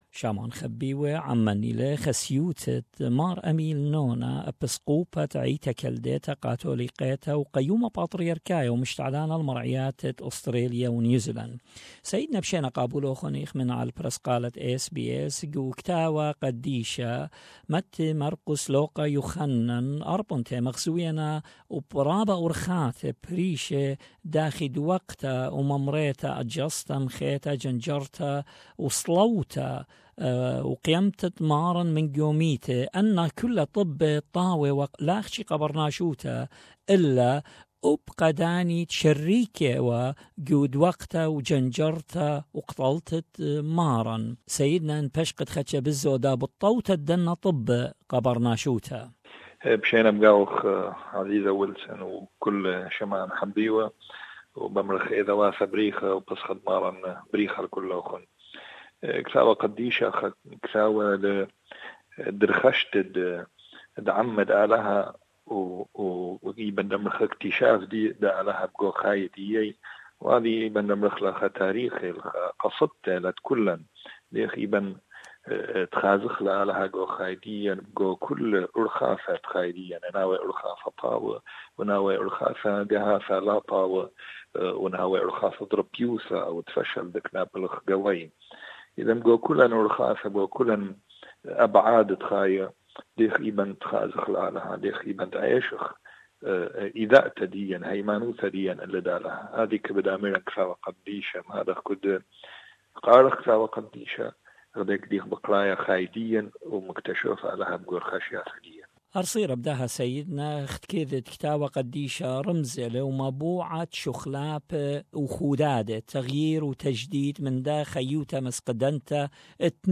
Listen to his grace Mar Ameel Nona, Archbishop of St Thomas the Apostle Chaldean and Assyrian Catholic Diocese of Australia and New Zealand Easter Message.